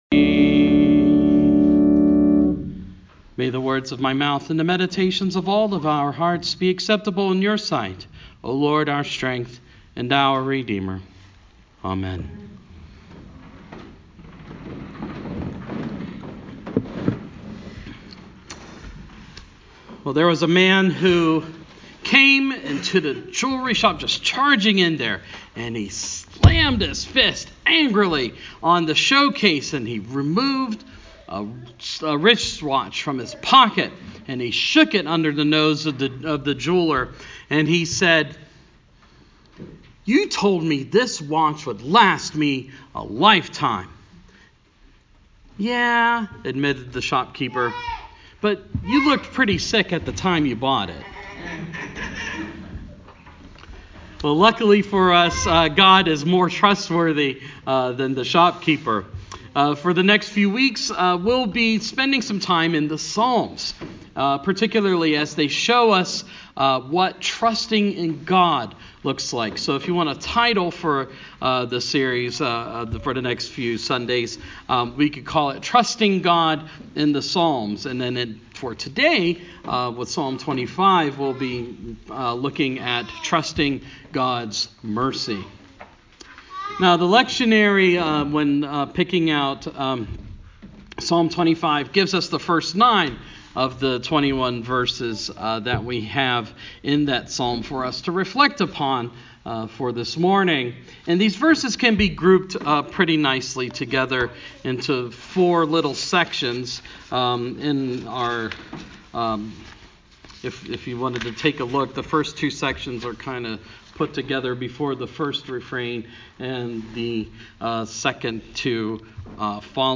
Sermon – Proper 21 – 2017